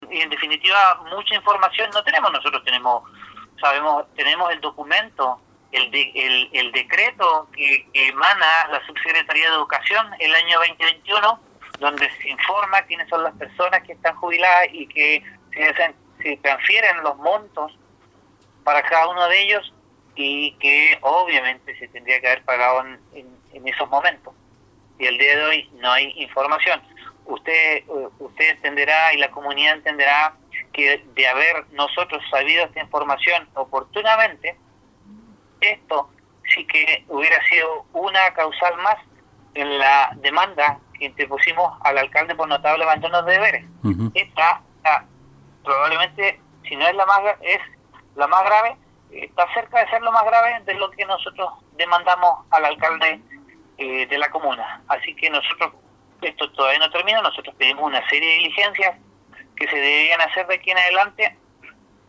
En primer término, así lo manifestó el concejal Rubén González, tras la sesión extraordinaria que vería esa materia y que tuvo lugar ayer miércoles, en la que se llevaron una desagradable sorpresa, puesto que no estuvo presente el alcalde Carlos Gómez, lo que fue duramente criticado.
31-CONCEJAL-RUBEN-GONZALEZ.mp3